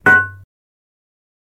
Metal Pipe, Crowbar Bang